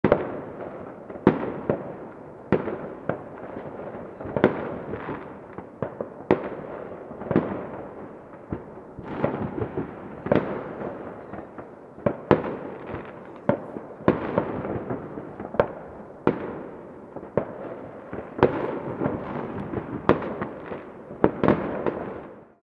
Realistic Fireworks Boom And Crackle Sound Effect
Description: Realistic fireworks boom and crackle sound effect. Constant fireworks explosions light up the sky, creating a festive and exciting atmosphere.
Genres: Sound Effects
Realistic-fireworks-boom-and-crackle-sound-effect.mp3